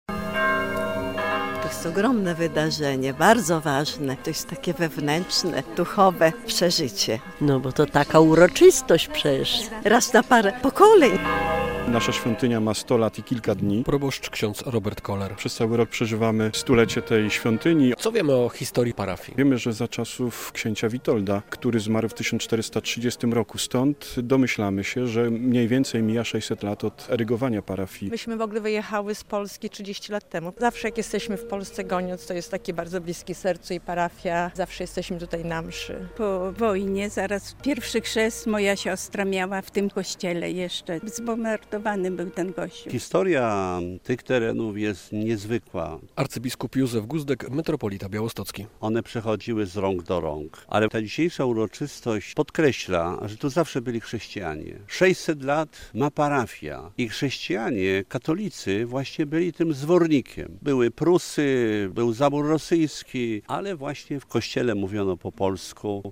To podsumowanie obchodów 600-lecia parafii św. Agnieszki w Goniądzu. W sobotę (21.09) o godz. 13:00 rozpoczęła się uroczysta msza pod przewodnictwem metropolity białostockiego, arcybiskupa Józefa Guzdka.
Jubileusz 600-lecia parafii pw. św. Agnieszki w Goniądzu - relacja